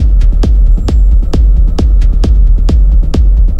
Как выкрутить такой воздушный пэд?
Подскажите как рулить такой/подобный пэд субтрактивным синтезом? (работаю на Analog Four) Похоже как само тело звука сильно размазано + добавлен шум.